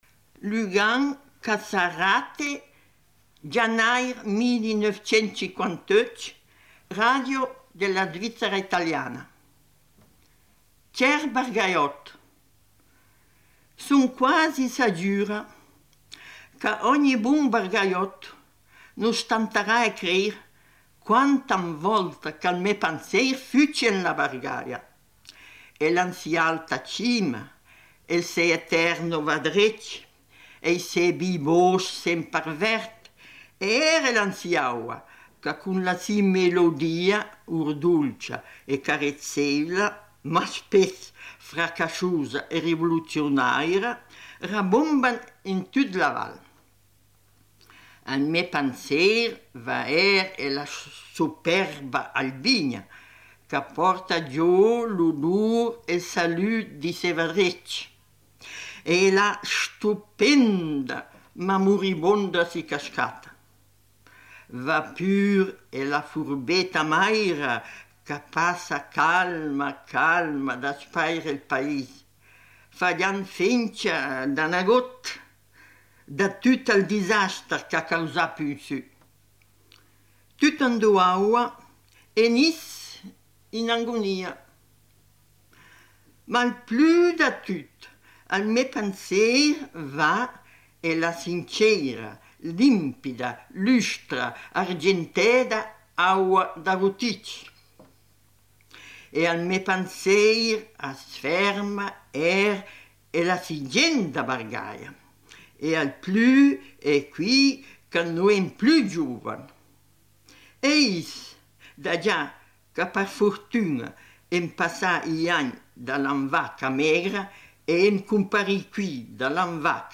“Voci del Grigioni italiano”, 1958